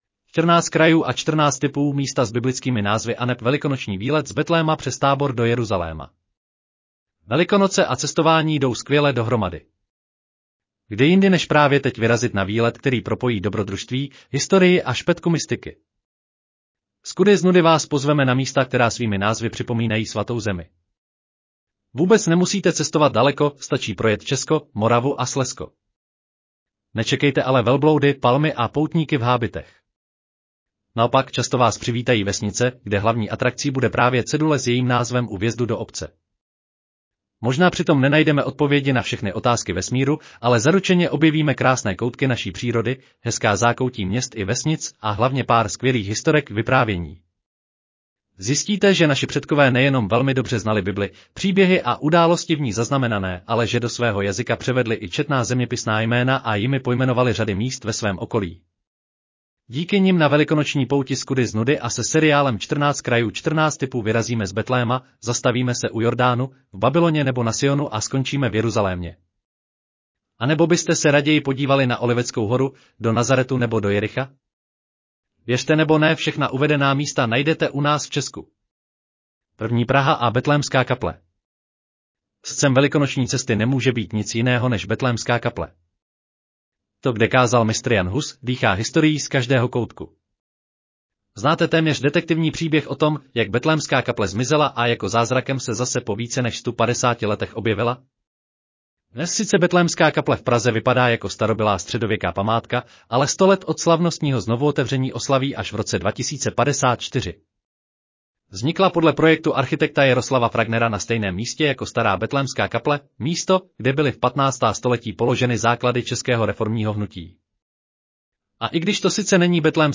Audio verze článku Čtrnáct krajů & čtrnáct tipů: místa s biblickými názvy aneb velikonoční výlet z Betléma přes Tábor d